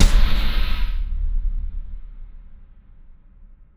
Big Drum Hit 33.wav